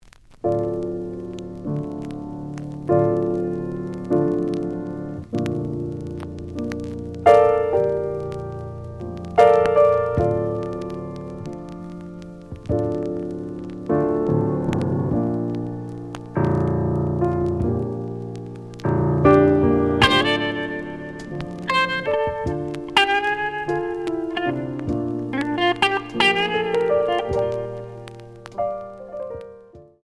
The audio sample is recorded from the actual item.
●Genre: Funk, 70's Funk
Slight noise on both sides.